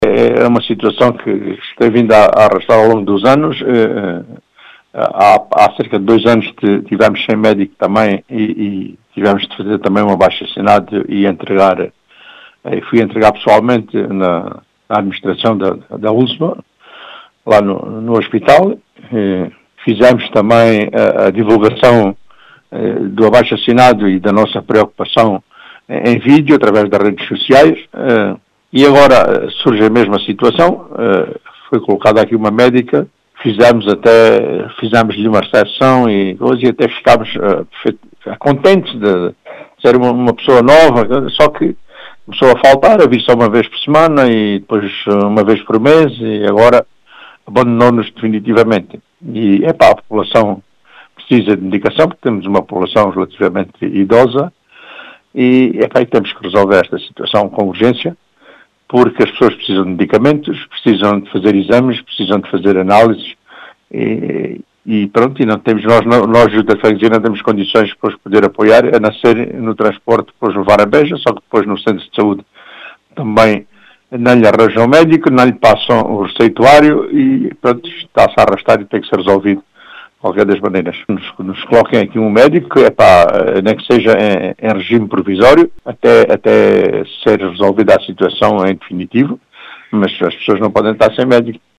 As explicações foram deixadas por Carlos Casimiro, presidente da União de Freguesias de Albernoa e Trindade que fala duma situação que “se tem vindo a arrastar ao longo dos anos”, e exige a resolução deste problema.